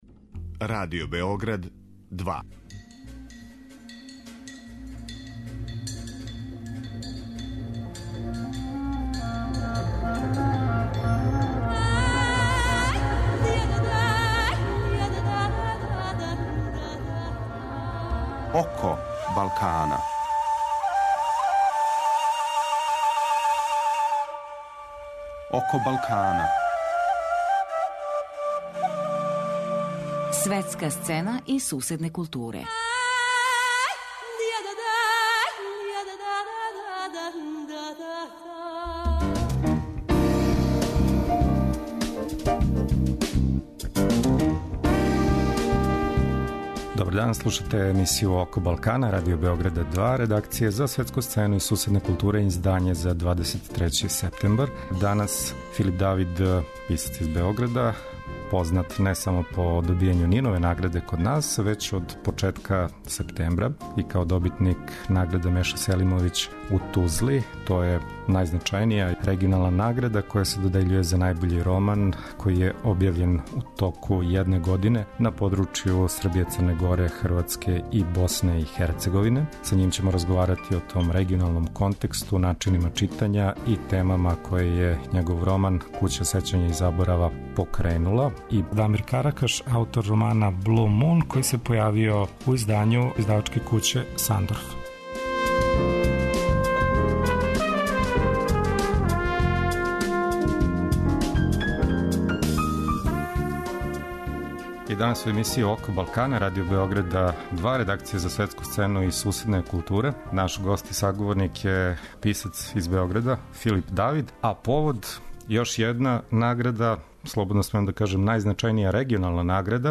Гост емисије је Филип Давид, добитник најважније регионалне награде "Меша Селимовић", на 15. међународним књижевним сусретима у Тузли.